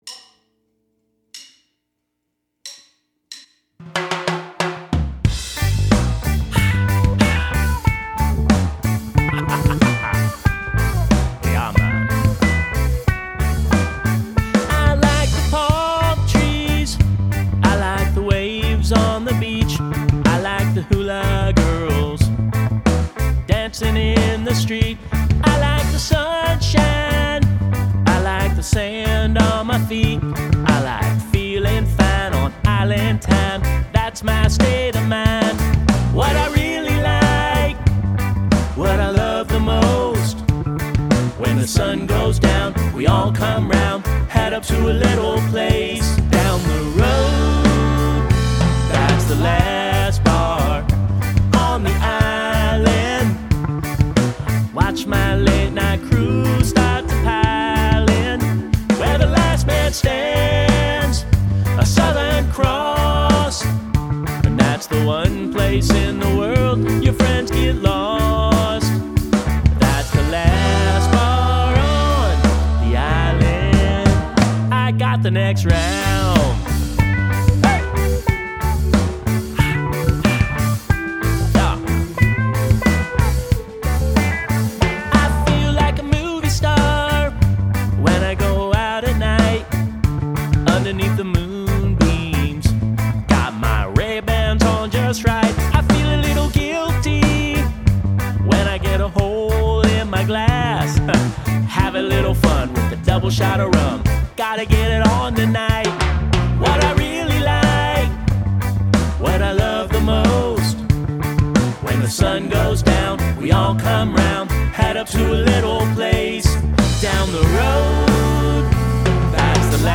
We’re looking for some wild high energy B3 to add some extra energy to the track. We’re doing sort of a rock reggae song, Main thing is we’re looking for fun.
update-wah-guitar-last-bar-on-the-island-bpm92-key-g.m4a